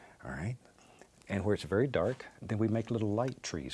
voices/eng/adult/male/BobRoss_24khz.wav